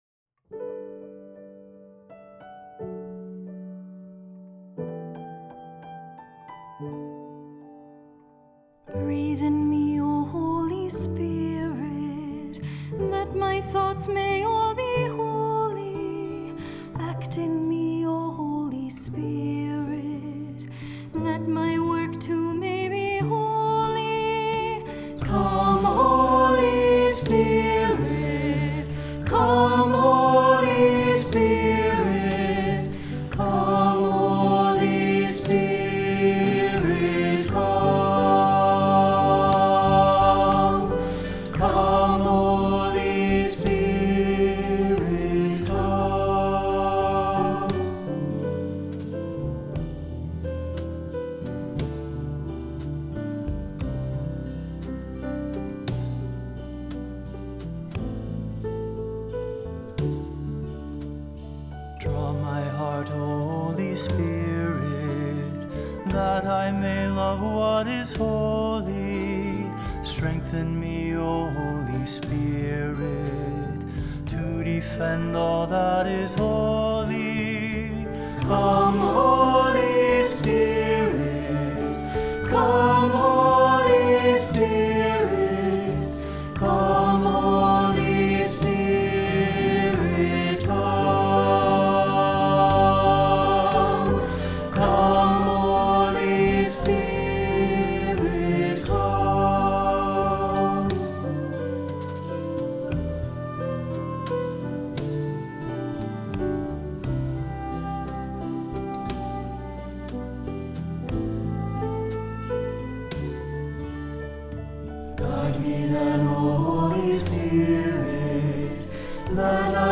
Voicing: Unison with descant; Assembly